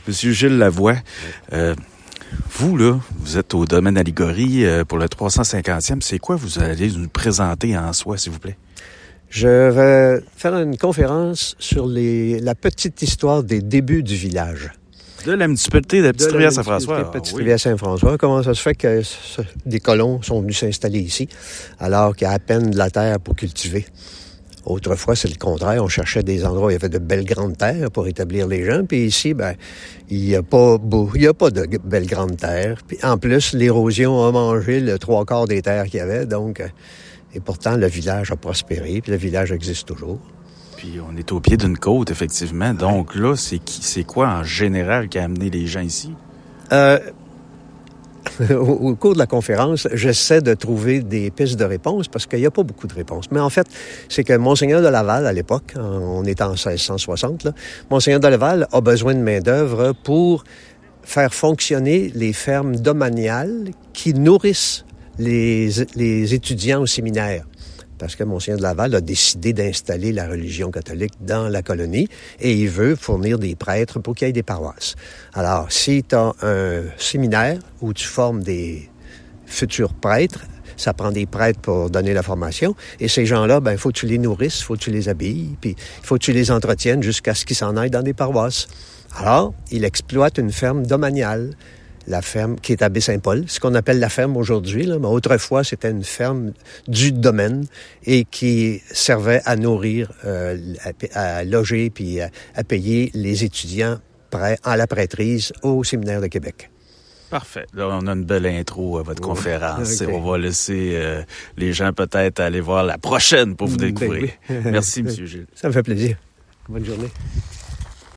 Après le succès de la veille, c’est encore au Domaine à Liguori que les amateurs d’histoire s’étaient donné rendez-vous.
En plein début d’après-midi, il a récité son savoir sur Petite-Rivière-Saint-François, documents à l’appui, devant une assistance attentive.